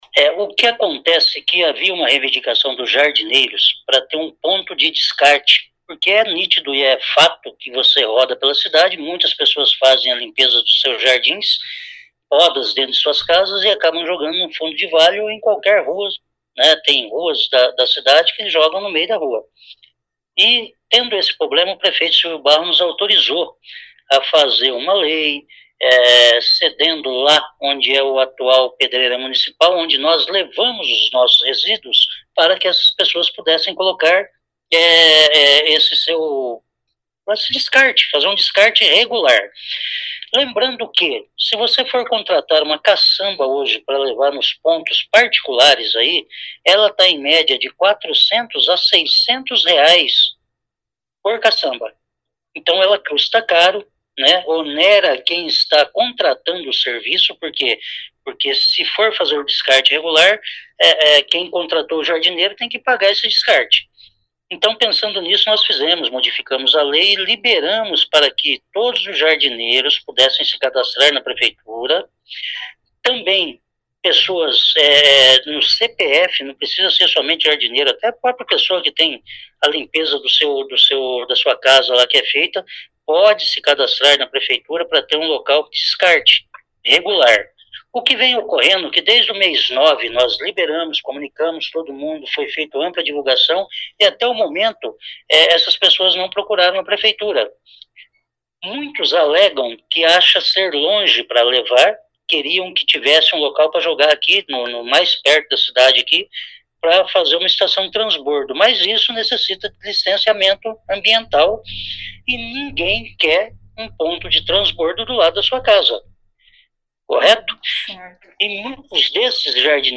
Ouça o que diz o secretário Vagner Mussio.